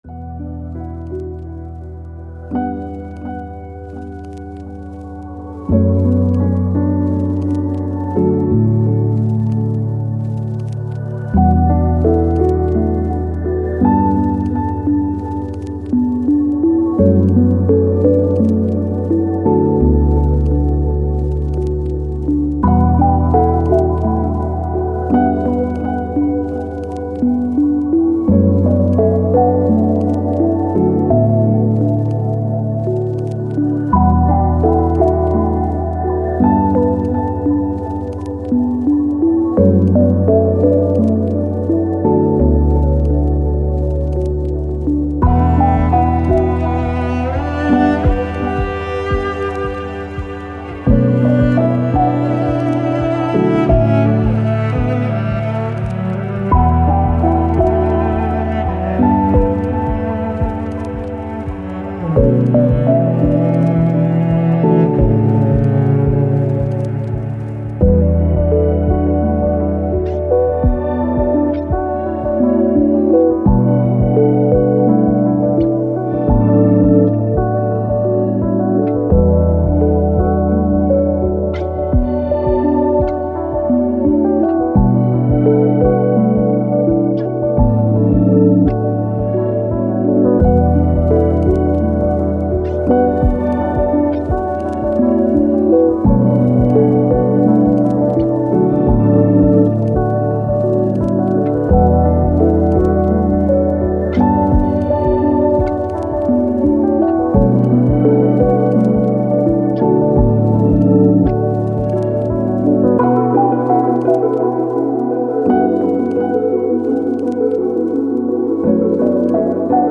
Ambient, Electronic, Soundtrack, Sad, Thoughtful